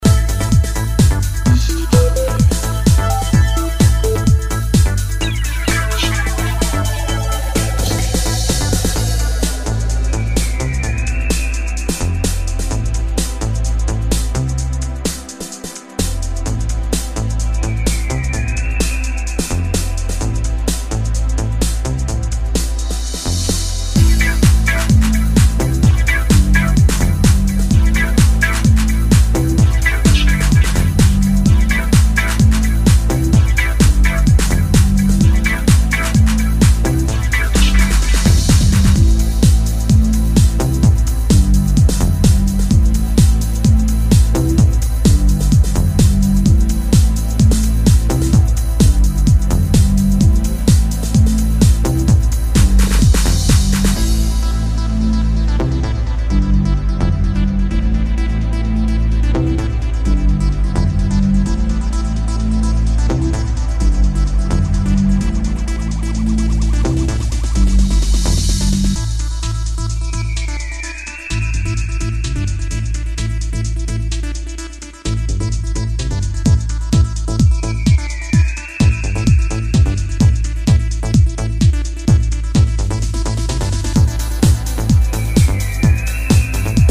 more electro-tinged territories than his previous material
modern house music